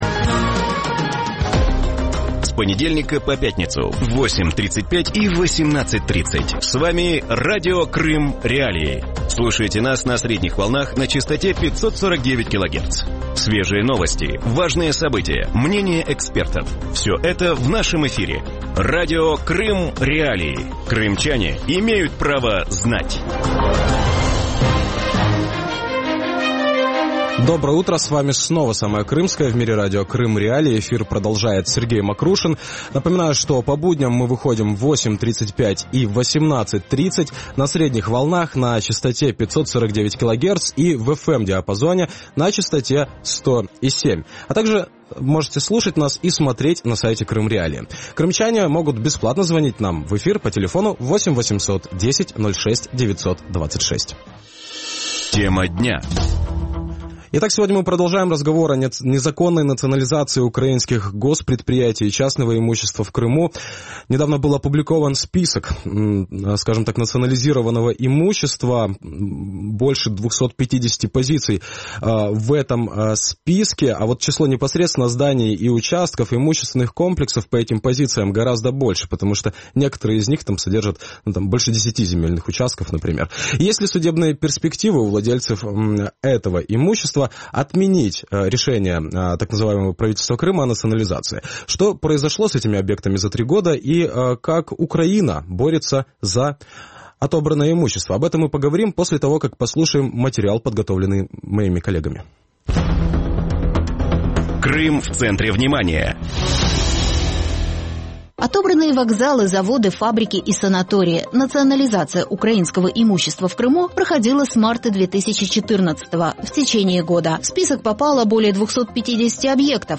Утром в эфире Радио Крым.Реалии говорят о незаконной «национализации» украинских предприятий, а также частного и государственного имущества в Крыму. Под «национализацию» попали порядка 300 объектов которые сегодня зарегистрированы согласно российскому законодательству. Есть ли судебные перспективы у владельцев отменить решение о «национализации»?